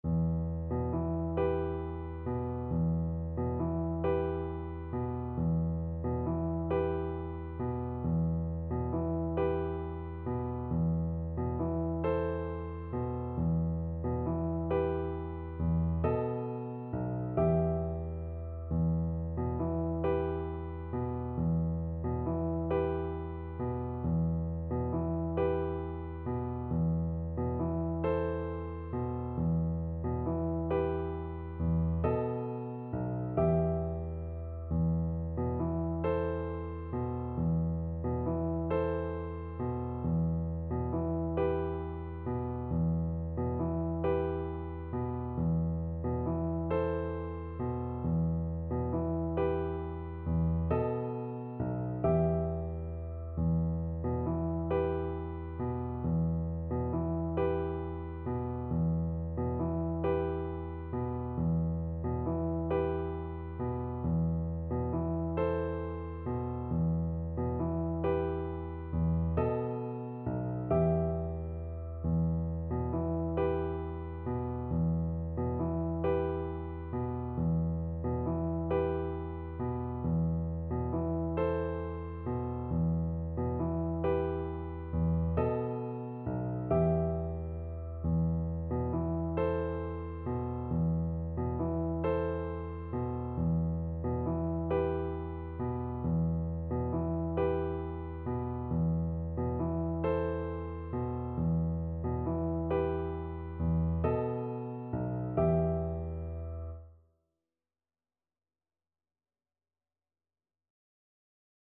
6/8 (View more 6/8 Music)
E minor (Sounding Pitch) (View more E minor Music for Viola )
Gently rocking .=c.45
Turkish